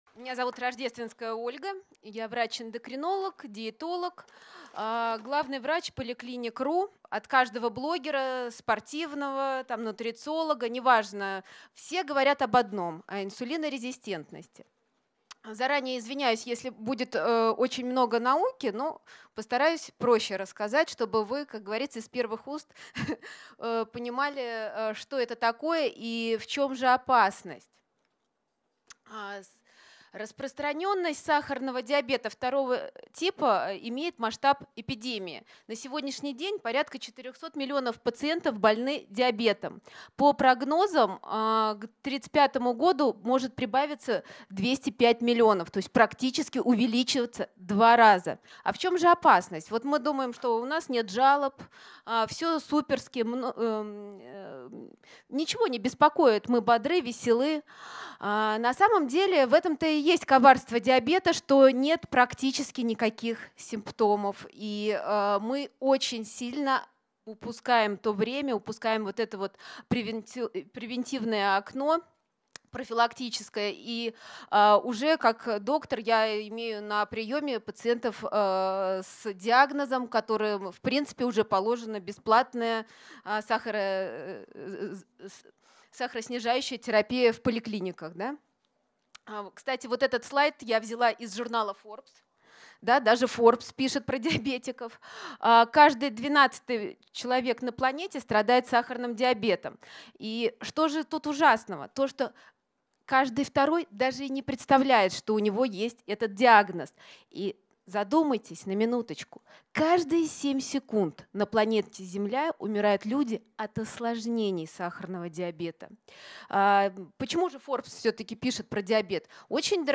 Лекция